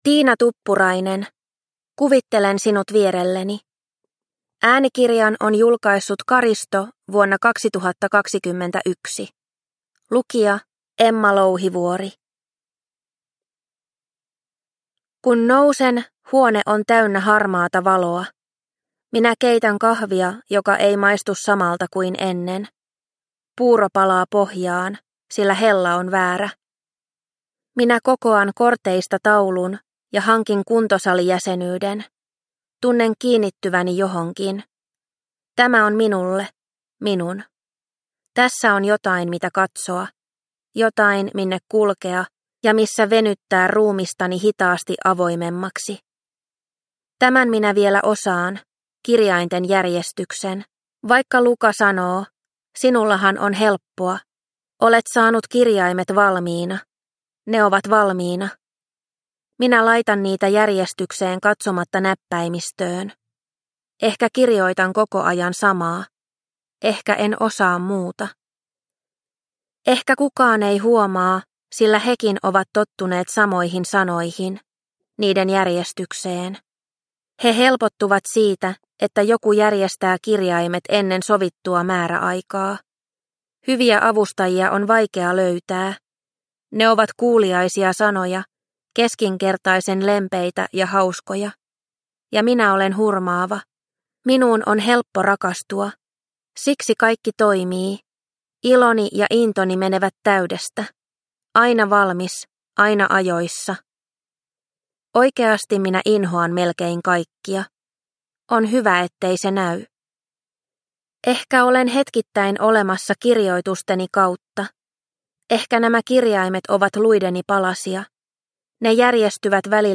Kuvittelen sinut vierelleni – Ljudbok – Laddas ner